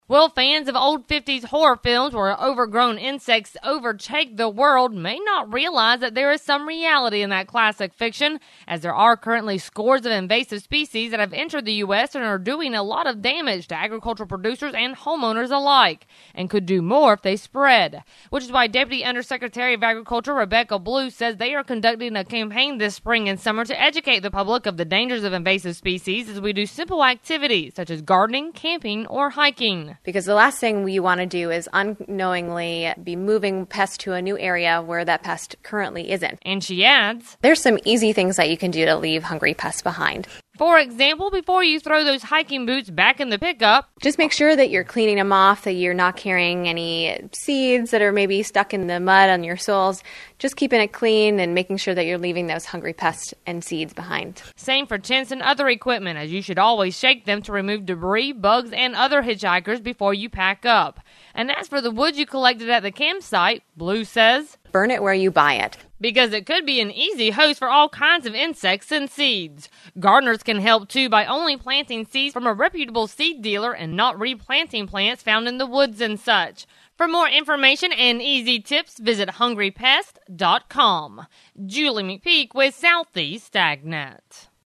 Deputy Under Secretary of Agriculture Rebecca Blue says they are conducting a campaign this spring and summer to educate the public of the dangers of invasive species.